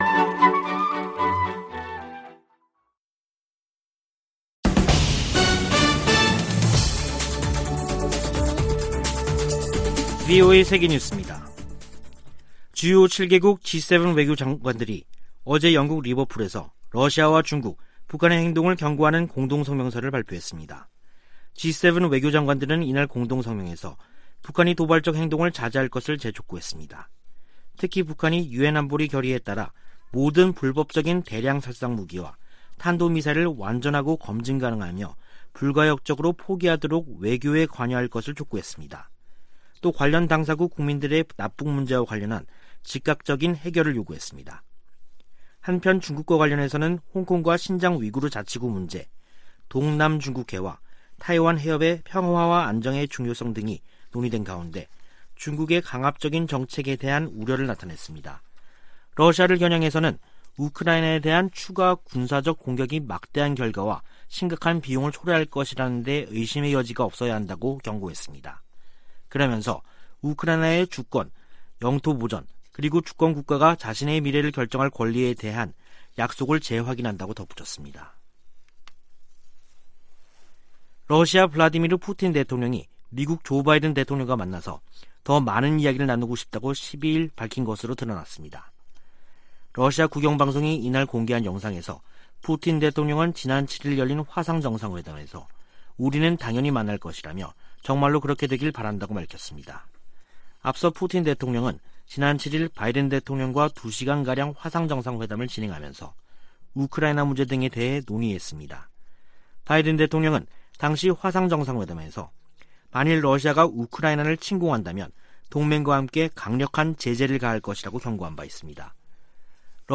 VOA 한국어 간판 뉴스 프로그램 '뉴스 투데이', 2021년 12월 13일 3부 방송입니다. 미 재무부가 리영길 국방상 등 북한과 중국, 미얀마 등에서 심각한 인권 유린을 자행한 개인과 기관들에 경제 제재를 부과했습니다. 문재인 한국 대통령은 베이징 동계 올림픽 외교적 보이콧을 고려하지 않고 있다면서 중국의 건설적 역할의 필요성을 강조했습니다. 미국이 북한에 코로나 백신을 주겠다고 제안한다면 북한이 대화의 장으로 나올 수도 있을 것이라고 한국 국가정보원장이 말했습니다.